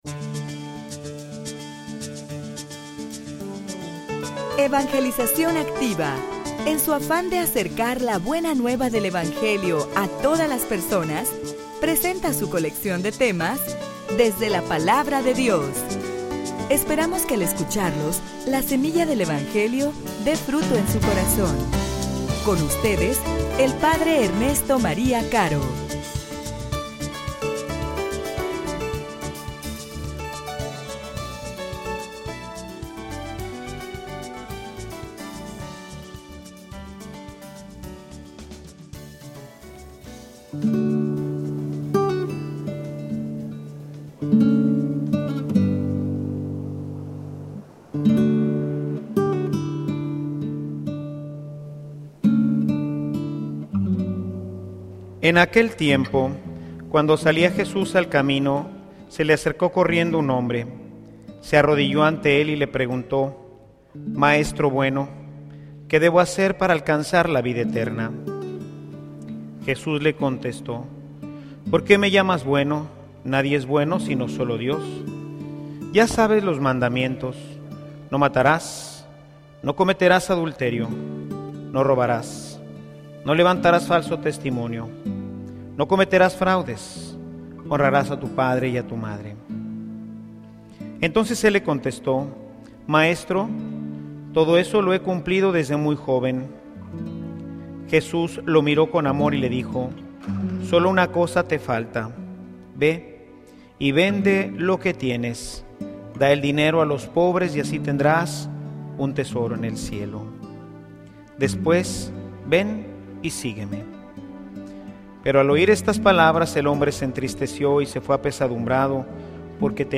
homilia_Jesucristo_centro_y_eje_de_nuestra_vida.mp3